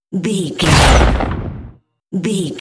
Dramatic hit thunder
Sound Effects
heavy
intense
dark
aggressive
hits